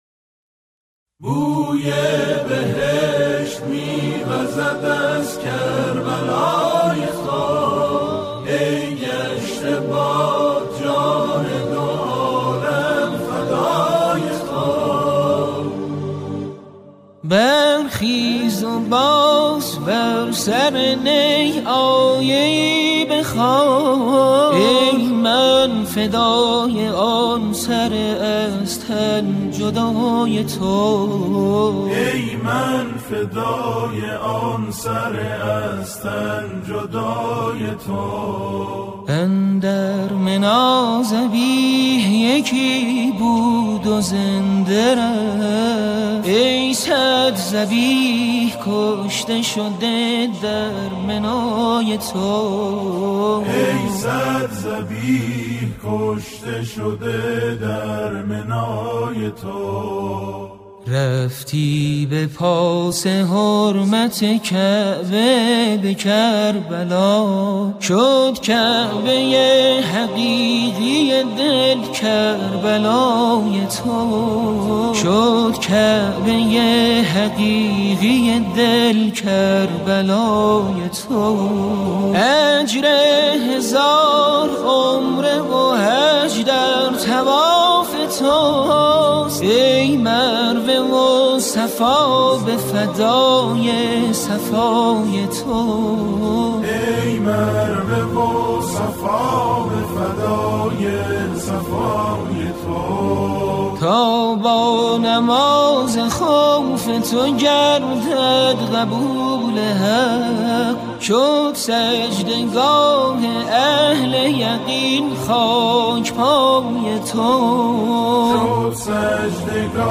گروهی از جمعخوانان اجرا می‌کنند